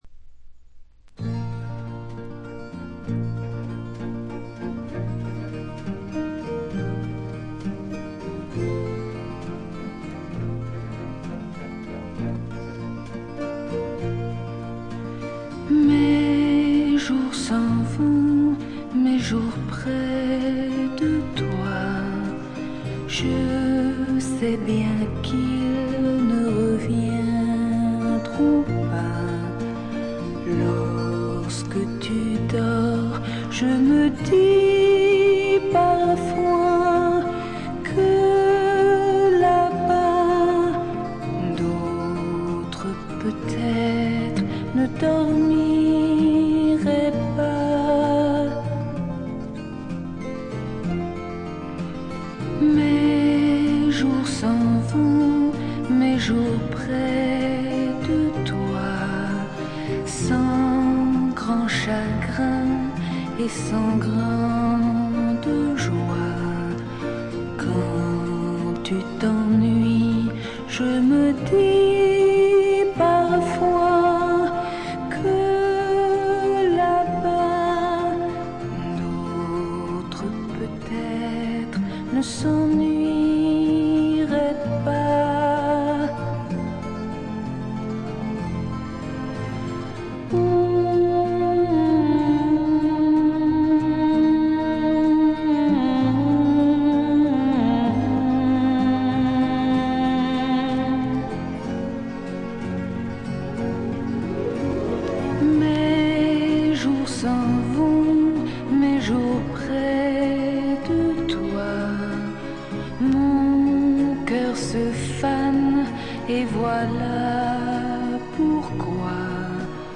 他はほとんどノイズ感なしで良好に鑑賞できます。
試聴曲は現品からの取り込み音源です。